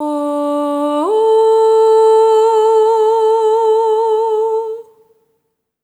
SOP5TH D4 -R.wav